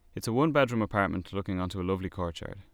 Dublin accent